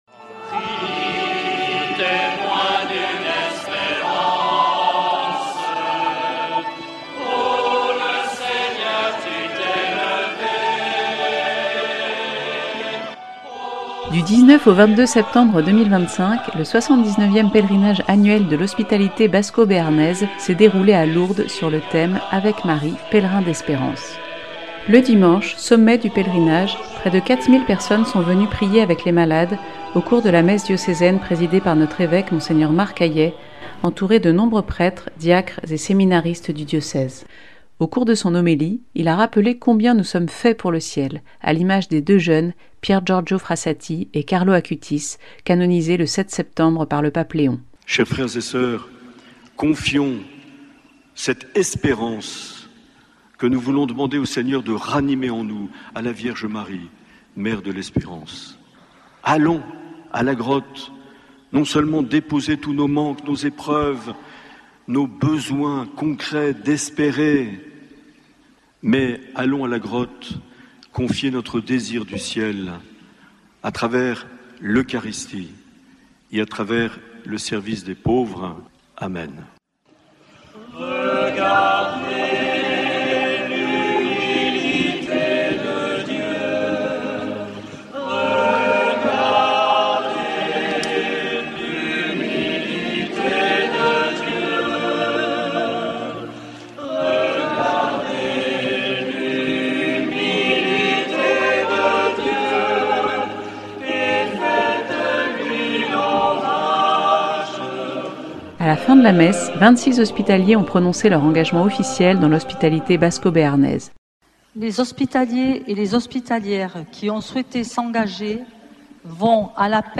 Au sein du pèlerinage de l’Hospitalité basco-béarnaise, le pèlerinage diocésain a rassemblé 4000 personnes à Lourdes le dimanche 21 septembre 2025. Reportage.